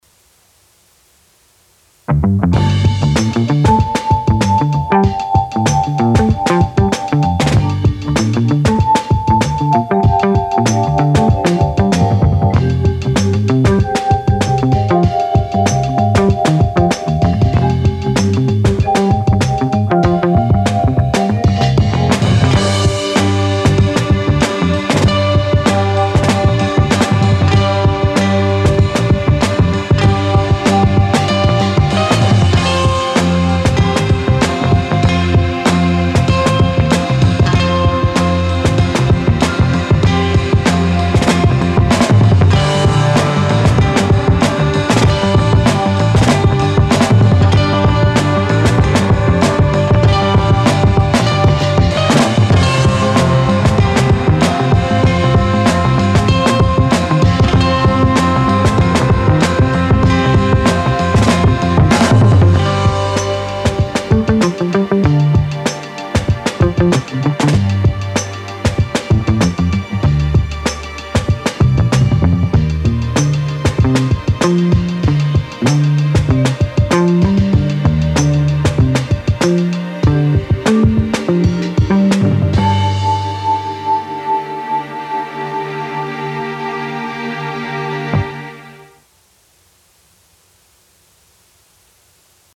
- 提供了一种独特的 Warped（扭曲）信号路径，它可以将原始的贝斯样本进行处理，产生一种低保真和富有特色的声音。